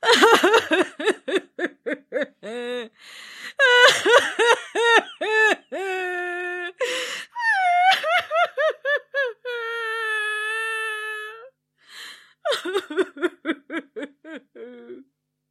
Звуки женского плача
Плач старушки